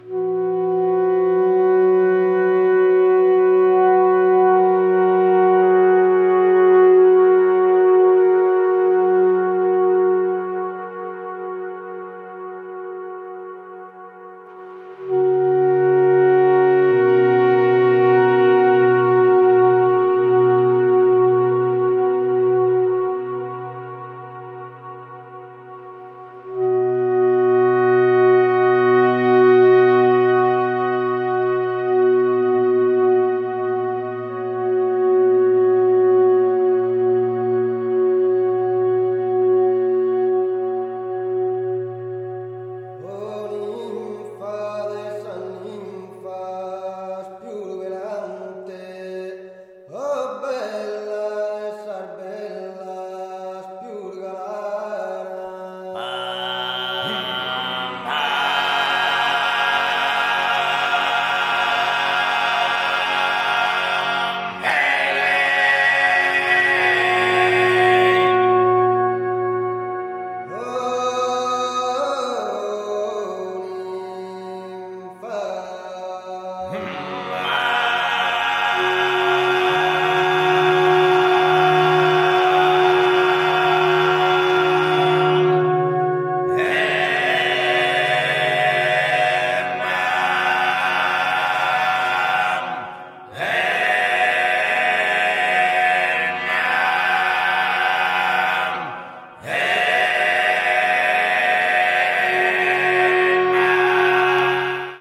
器楽と合唱が美しく融合した楽曲を楽しめますよ！